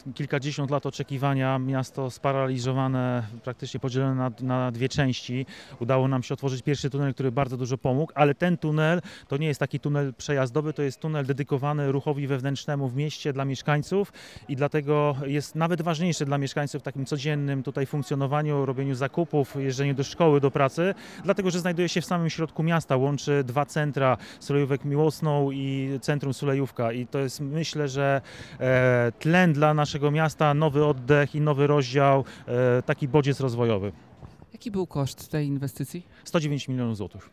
Remigiusz Górniak, starosta miński zwraca uwagę, że oczekiwanie na tunel trwało kilkadziesiąt lat.